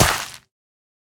Minecraft Version Minecraft Version 1.21.5 Latest Release | Latest Snapshot 1.21.5 / assets / minecraft / sounds / block / suspicious_gravel / step4.ogg Compare With Compare With Latest Release | Latest Snapshot
step4.ogg